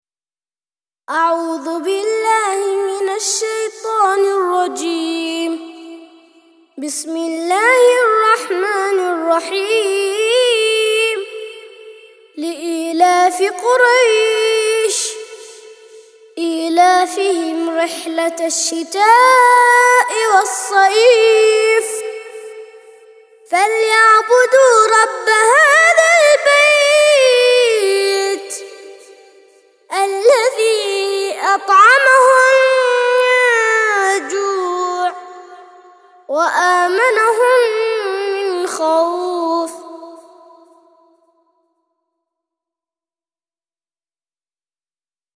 106- سورة قريش - ترتيل سورة قريش للأطفال لحفظ الملف في مجلد خاص اضغط بالزر الأيمن هنا ثم اختر (حفظ الهدف باسم - Save Target As) واختر المكان المناسب